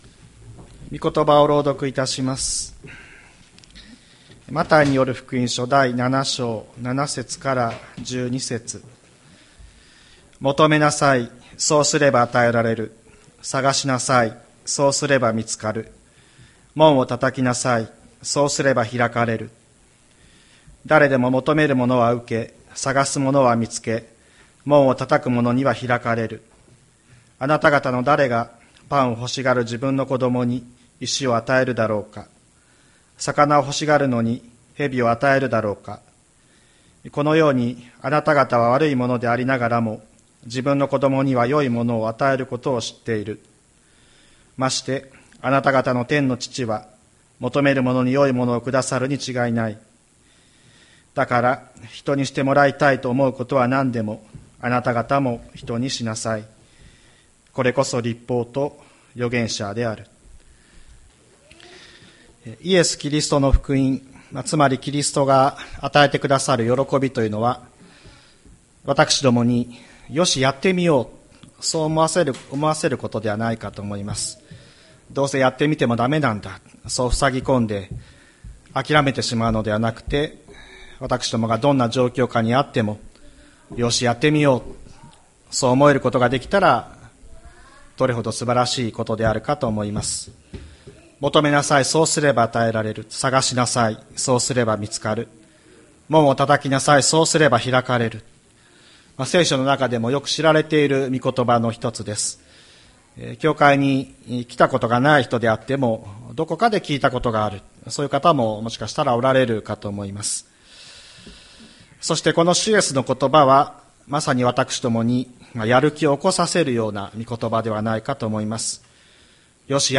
2023年10月15日朝の礼拝「求めれば与えられる」吹田市千里山のキリスト教会
千里山教会 2023年10月15日の礼拝メッセージ。